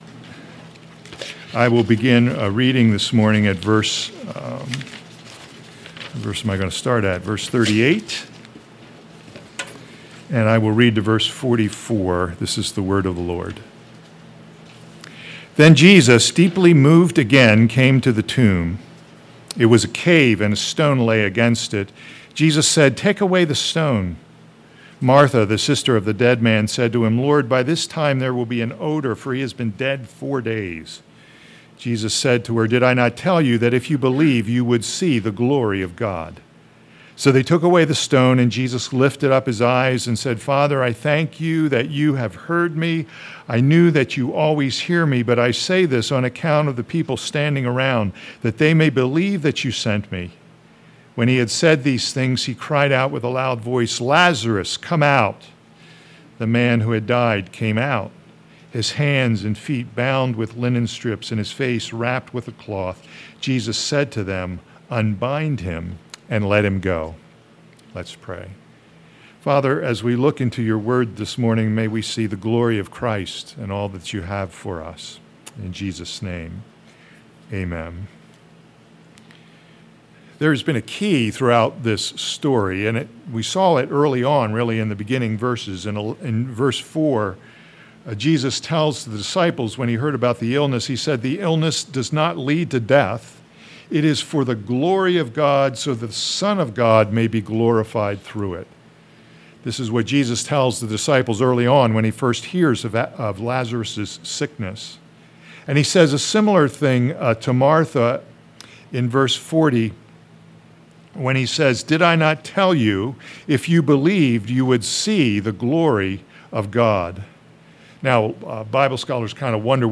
All Sermons The Glory of God and the Resurrection of Lazarus